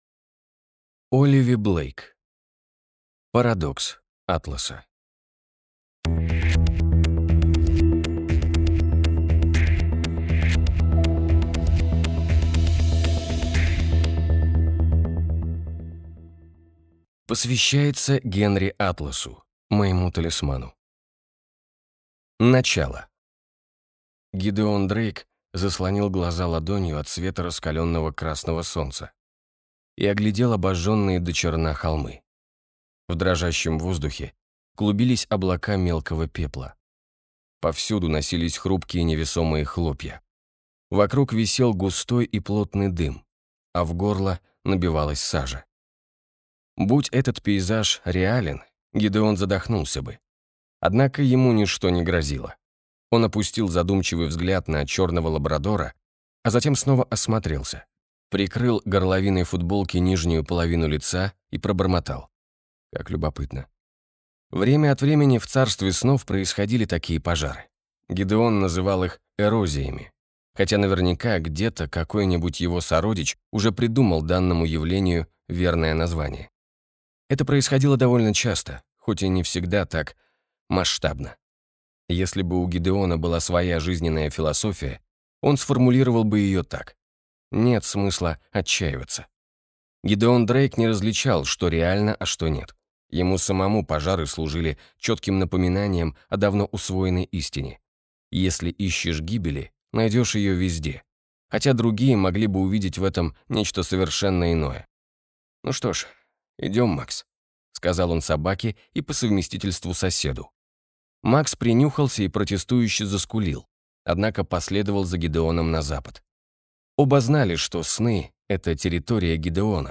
Аудиокнига Парадокс Атласа | Библиотека аудиокниг